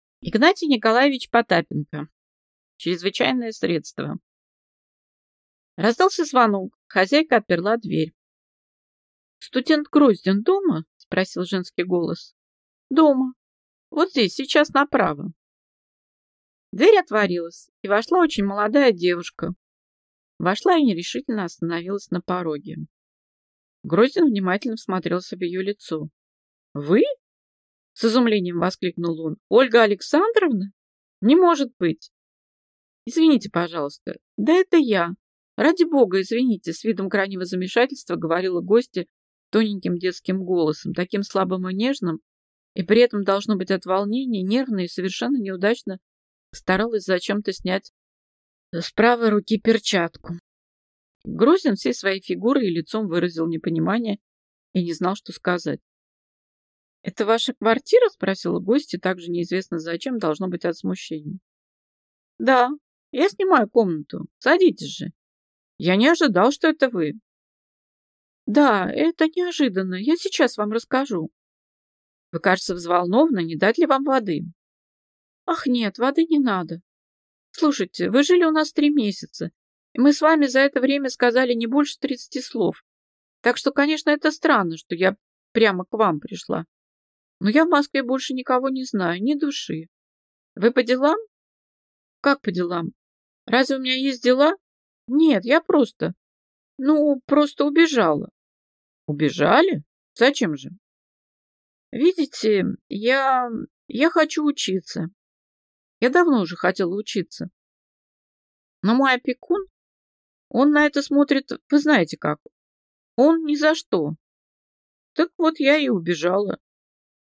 Аудиокнига Чрезвычайное средство | Библиотека аудиокниг